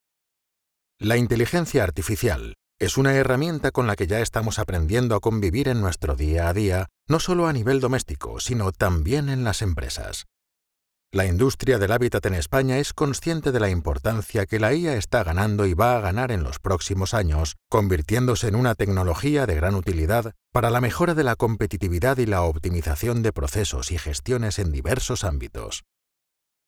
Unternehmensvideos
Mikrofon: Neumann TLM-103
Im mittleren Alter
Bass